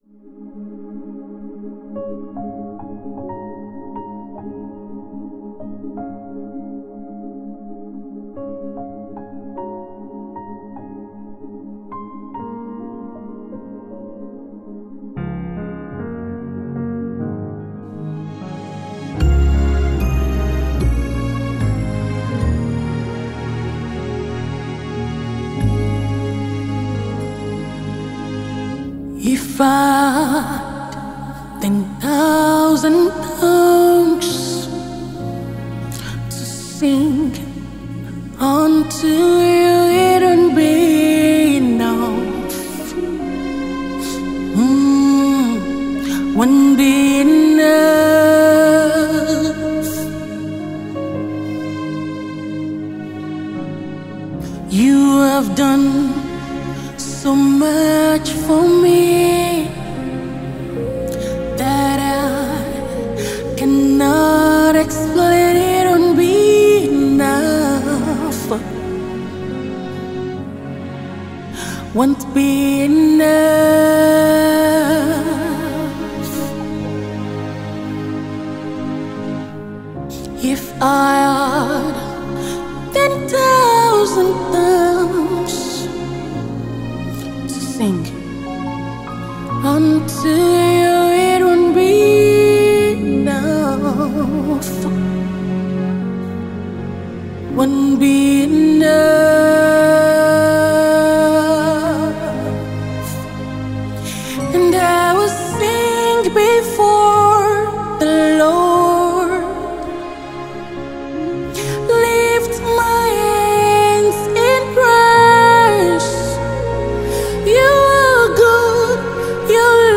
Nigerian talented gospel singer and songwriter